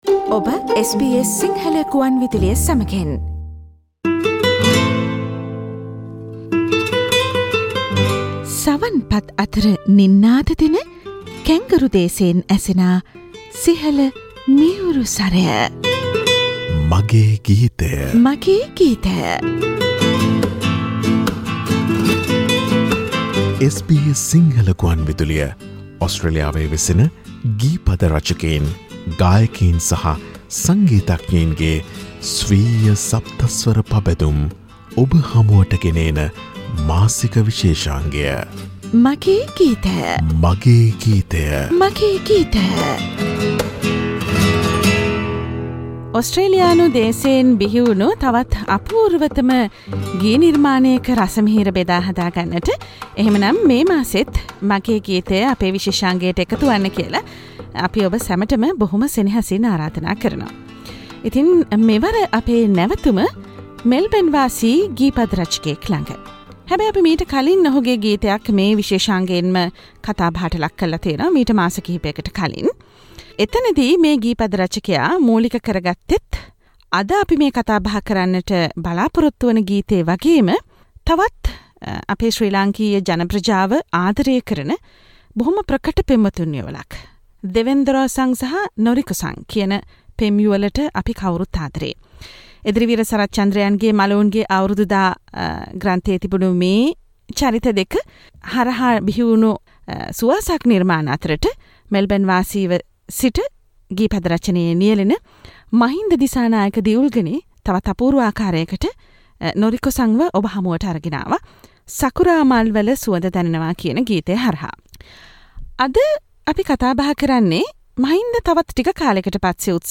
Sinhala Radio monthly program- ‘Moy Song’ Source: SBS Sinhala